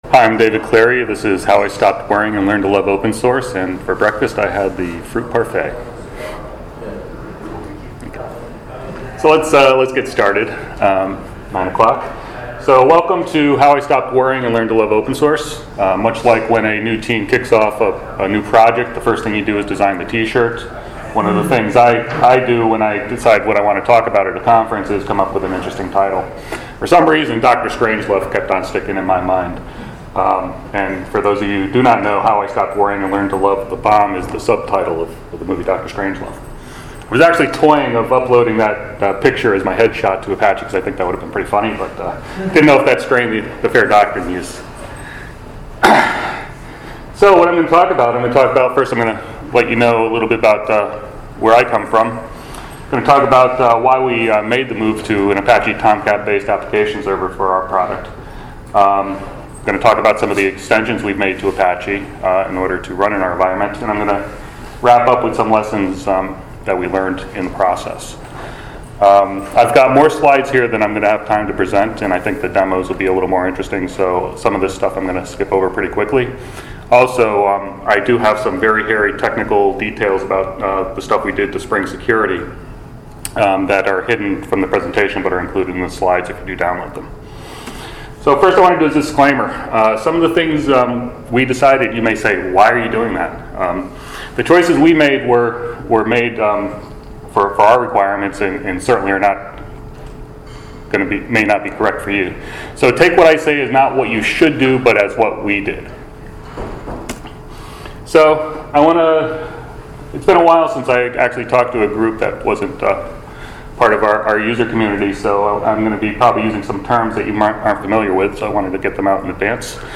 ApacheCon Miami 2017 – How I Stopped Worrying and Learned to Love Open Source